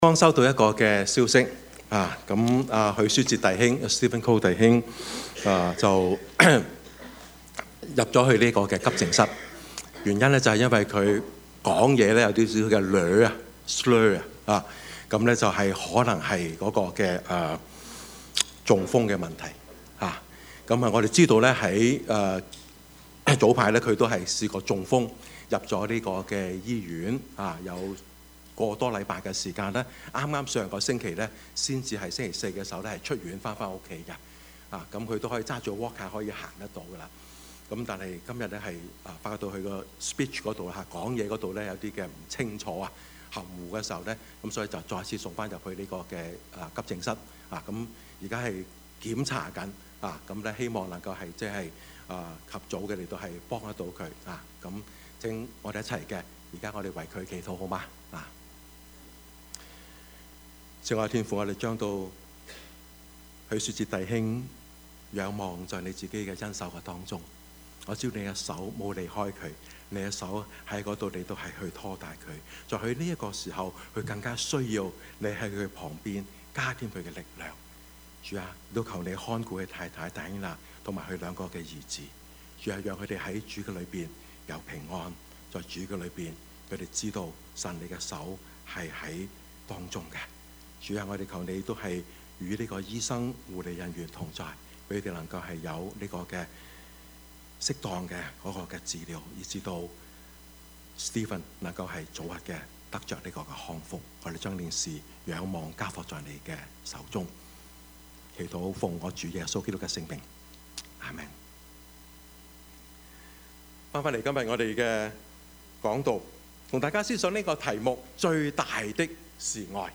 Service Type: 主日崇拜
Topics: 主日證道 « 大毛巾?